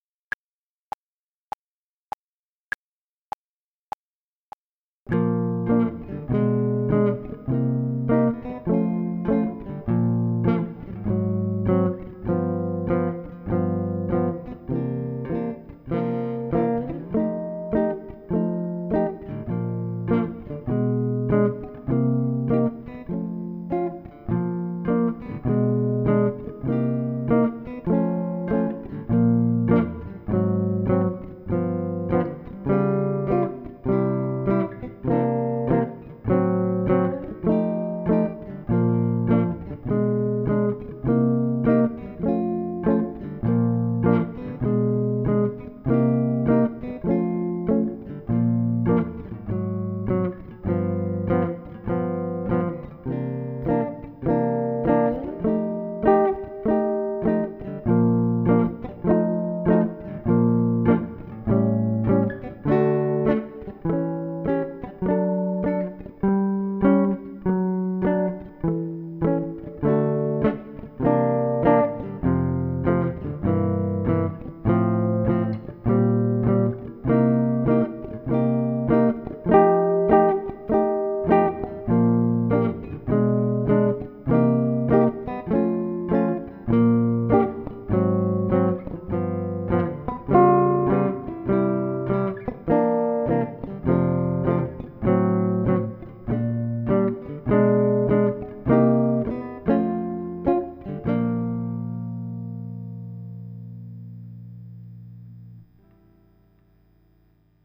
Salut à tous, j'ai enregistré des playbacks pour bosser :
Départ avec 2 mesures de clic - Grille complète
• Tempo 100 :